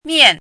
怎么读
miàn
mian4.mp3